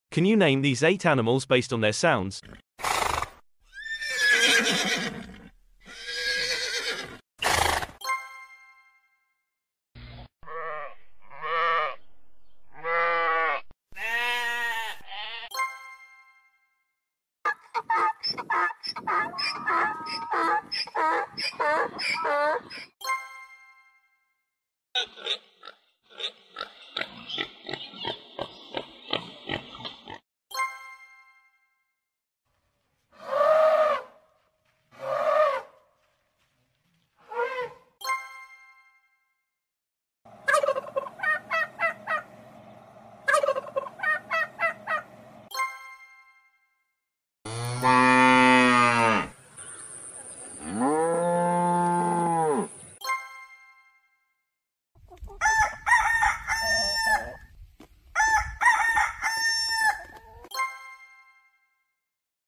Can you recognize animals based sound effects free download